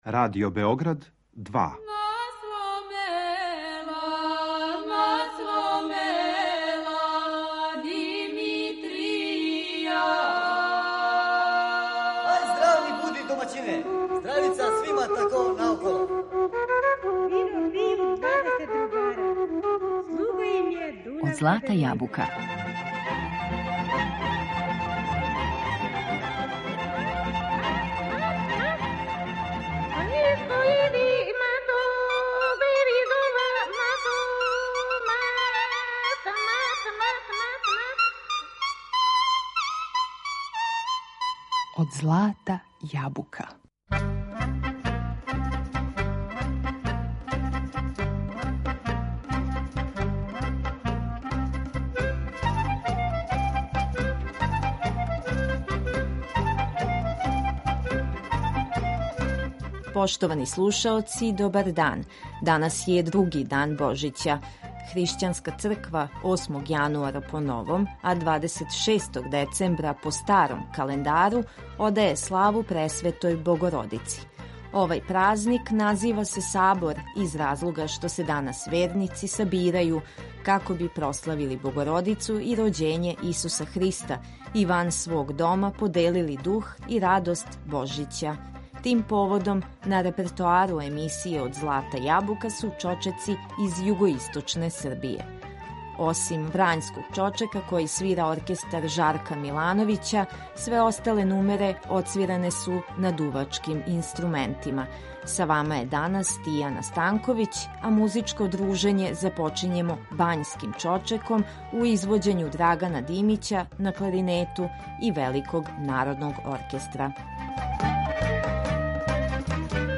Тим поводом, на репертоару емисије биће чочеци из југоисточне Србије.